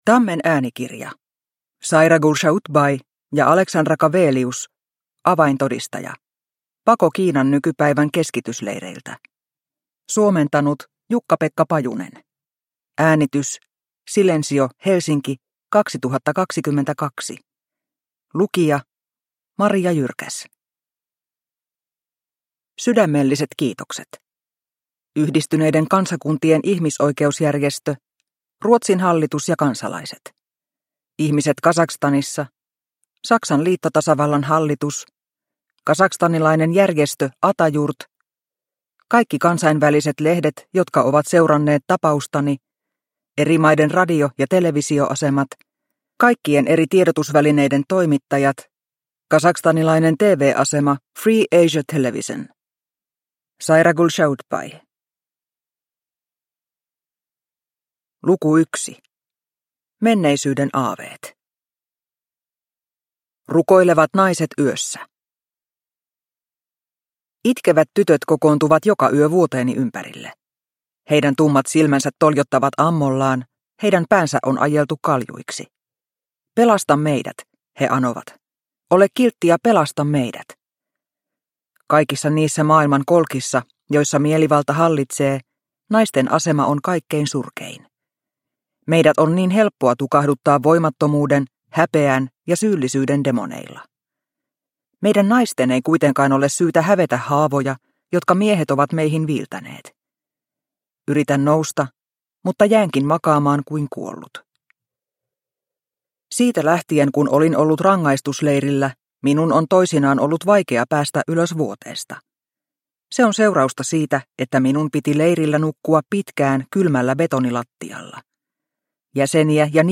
Avaintodistaja – Ljudbok – Laddas ner